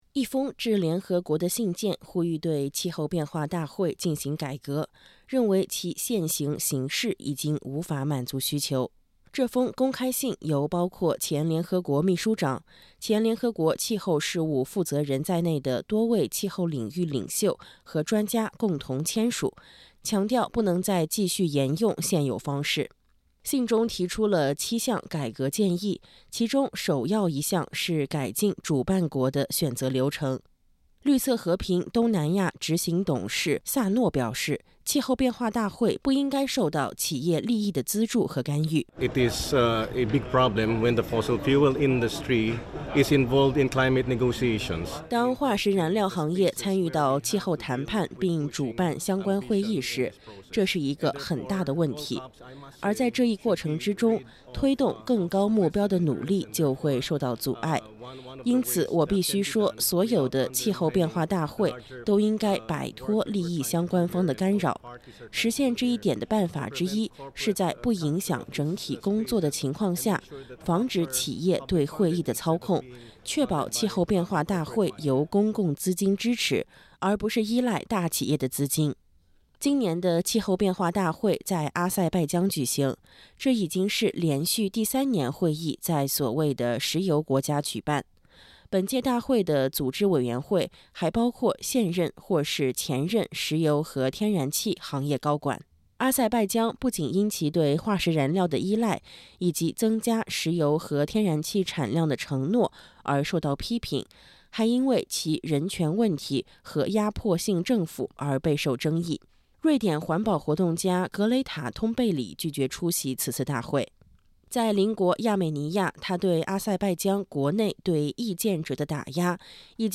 在致联合国的一封公开信中，前外交官和气候领域的知名专家呼吁对这一年度大会进行紧急改革，因为全球在实现减排目标的道路上进展缓慢。点击音频，收听完整报道。